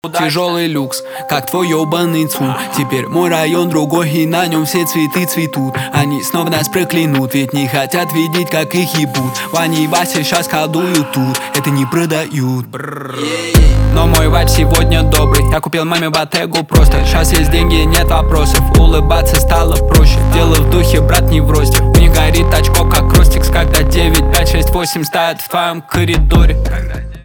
русский рэп
битовые , басы , качающие
жесткие